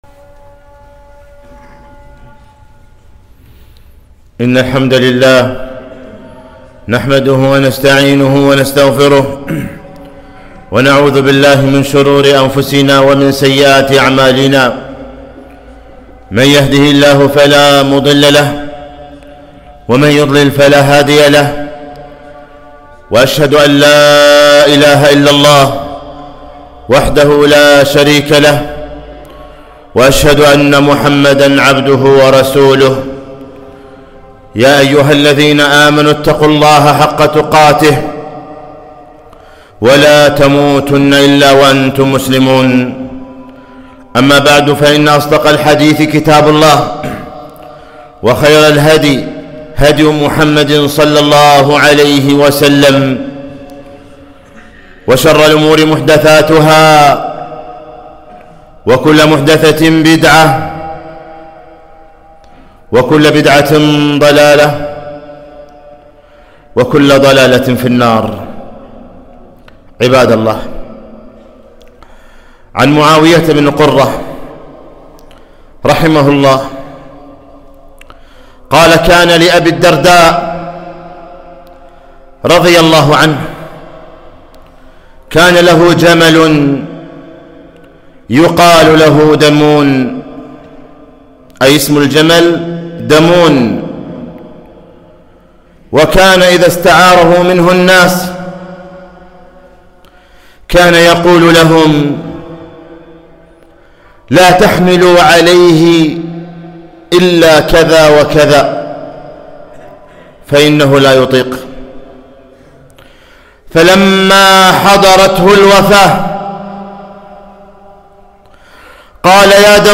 خطبة - احذر الخصوم يوم القيامة